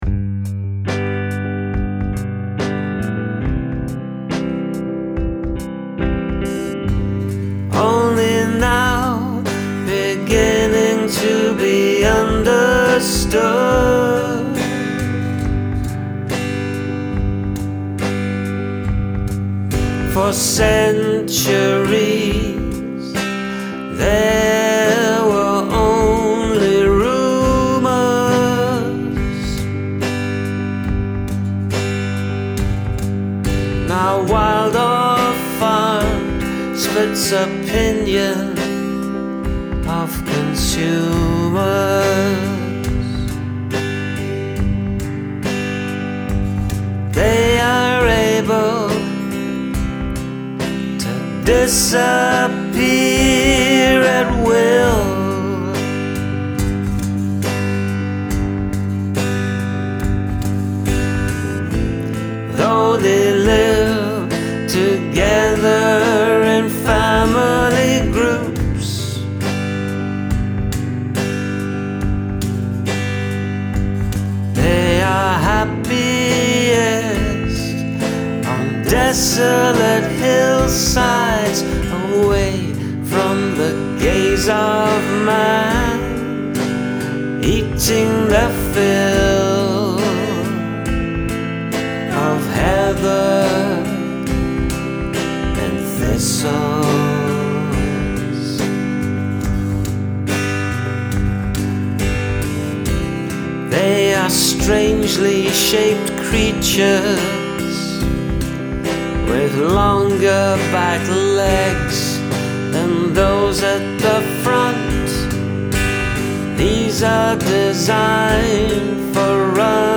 I am stumped by the actual subject matter but I sat in for the mellow mood